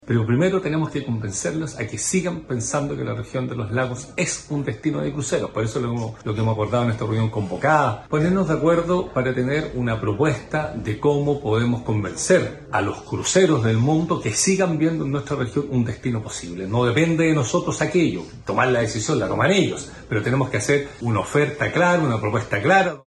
Por su parte, el gobernador de Los Lagos, Patricio Vallespín, sostuvo que la idea es convencer a empresas de cruceros que sigan teniendo a la zona en sus recorridos.